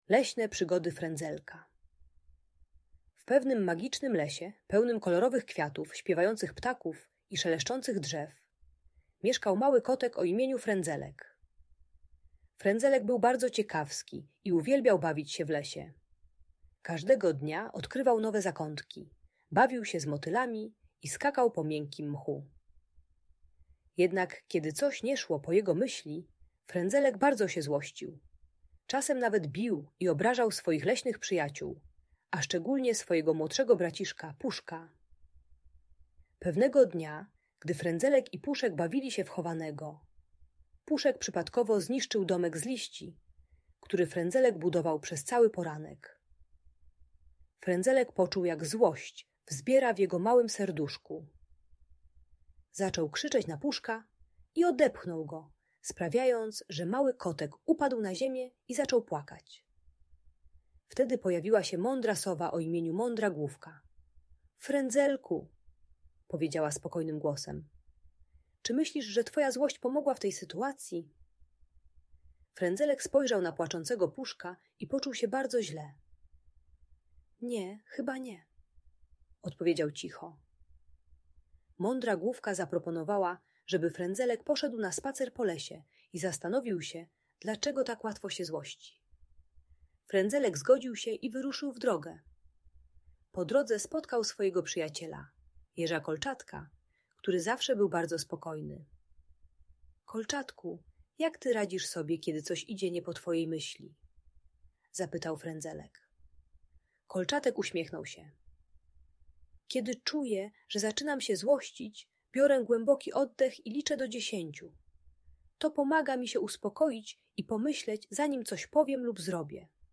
Leśne Przygody Frędzelka - Urocza historia o emocjach - Audiobajka dla dzieci